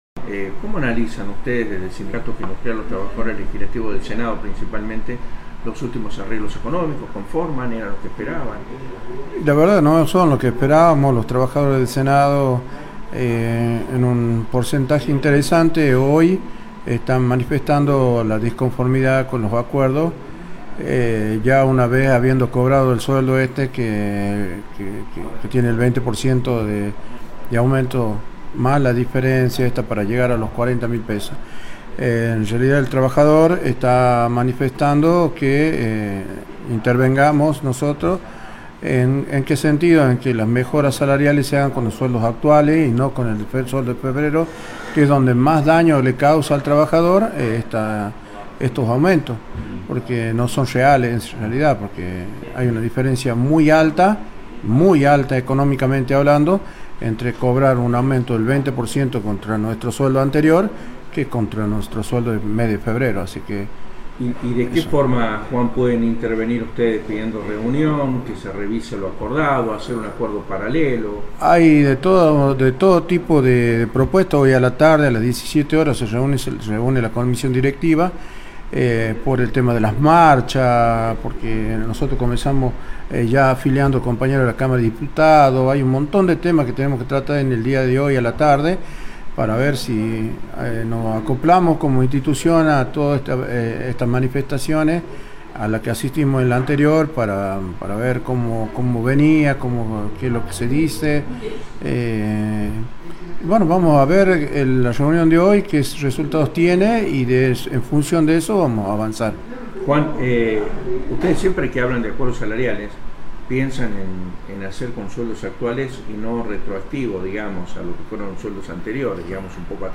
Entrevistas CityRadio CiTy Entrevistas